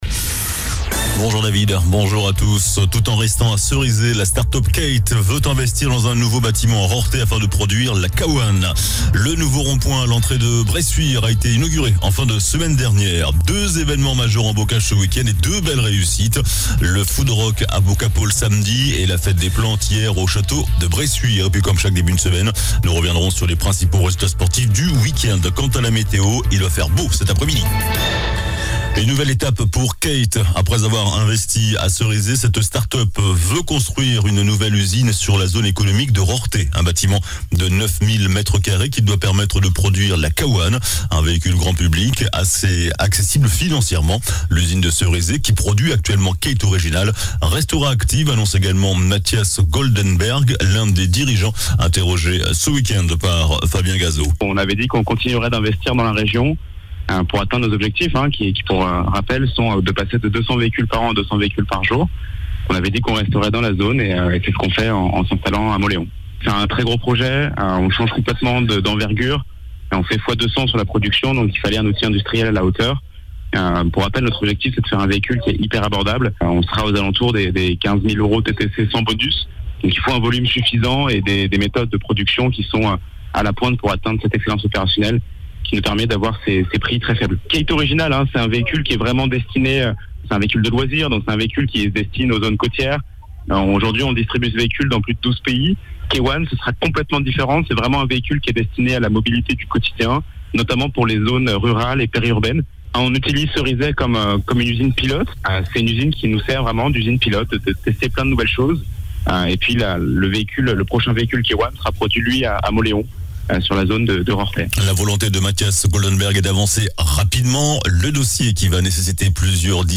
JOURNAL DU LUNDI 02 OCTOBRE ( MIDI )